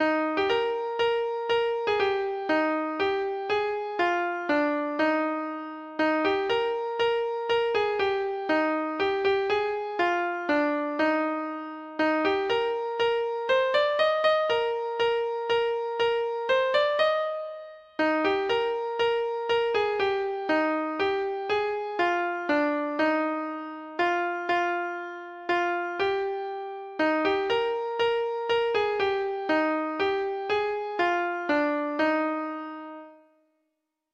Collected in Cornwall.